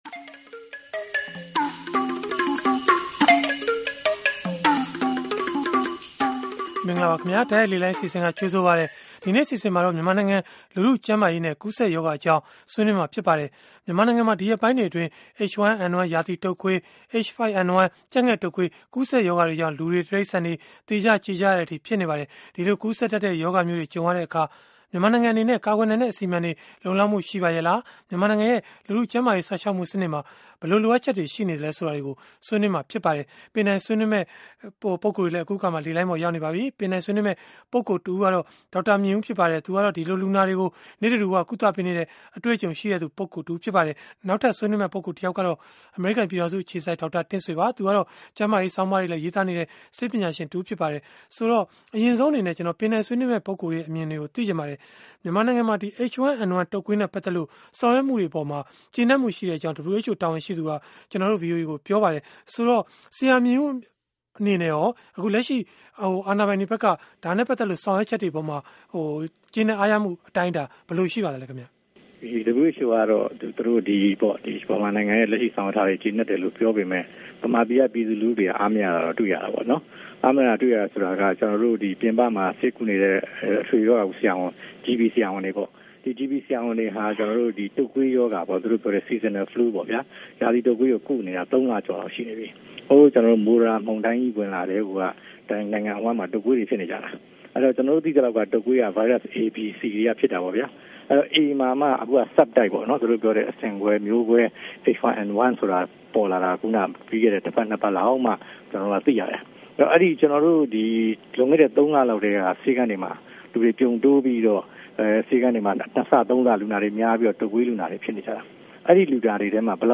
ဗွီအိုအေရဲ့ စနေနေ့ည တိုက်ရိုက်လေလှိုင်း အစီအစဉ်မှာ